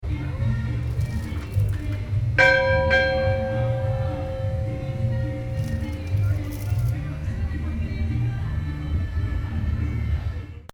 In an alleyway: an arc welder being used to fix a vending cart, music and voices spilling from the doorways, the bell
juarez-zap.mp3